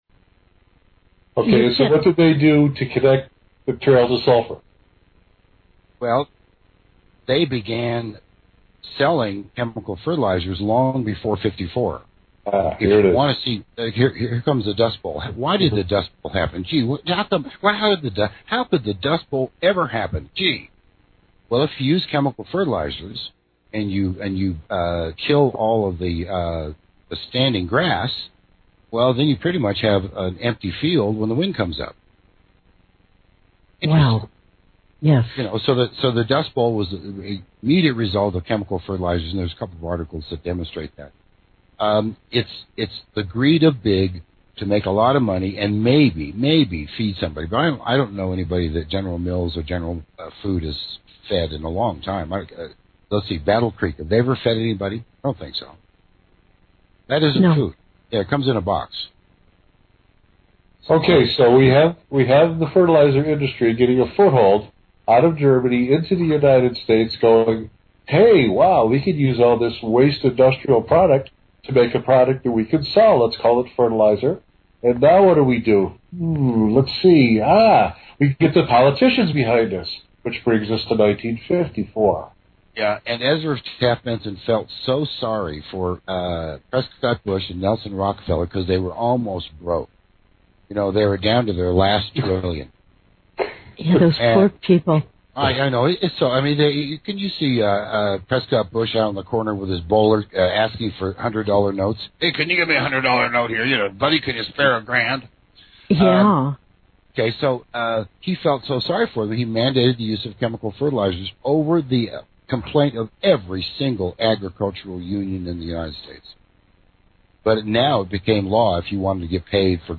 Interview made available with permission of Radio RMN .